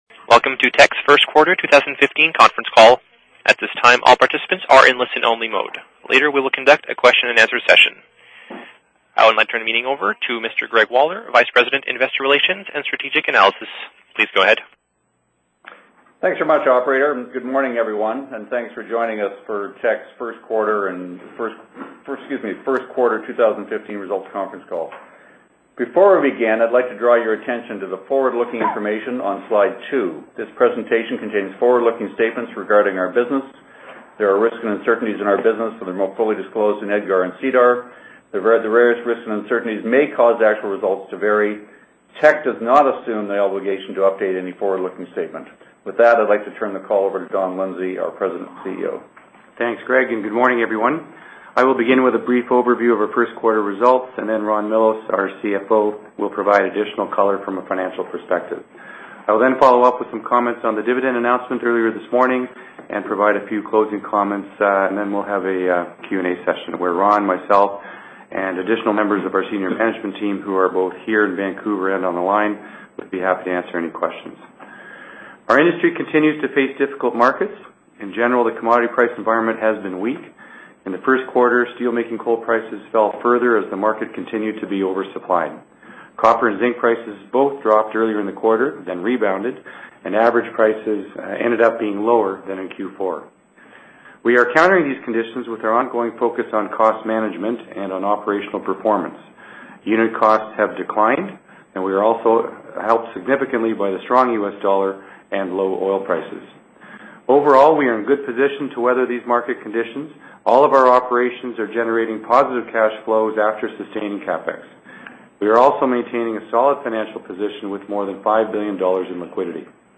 Q1 Financial Report 2015 [PDF - 1.91 MB] Q1 Financial Report Conference Call Presentation Slides [PDF - 1.21 MB] Q1 2015 Conference Call Audio File [MP3 - 21.69 MB] Q1 Financial Report Conference Call Transcript [PDF - 0.27 MB]